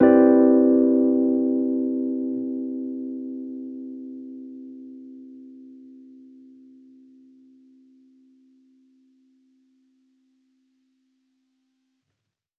Index of /musicradar/jazz-keys-samples/Chord Hits/Electric Piano 1
JK_ElPiano1_Chord-Cmaj9.wav